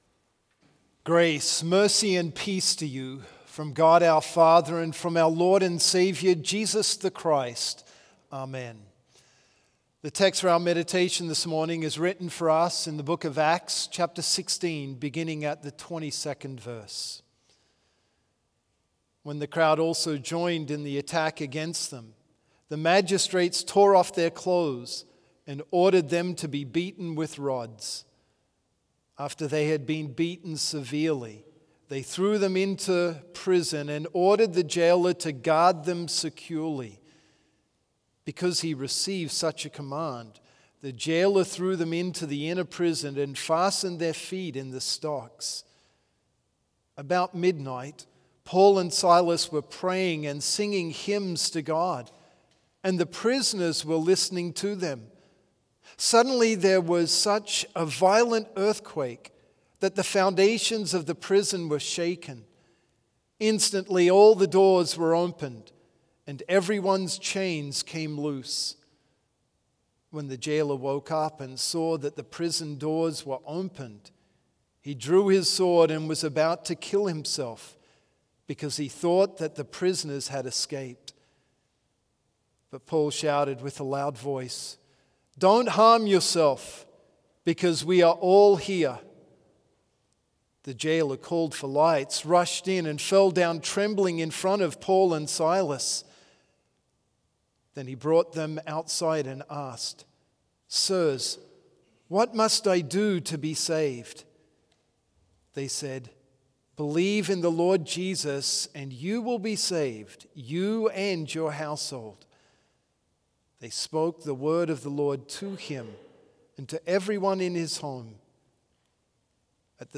Complete service audio for Chapel - Monday, February 3, 2025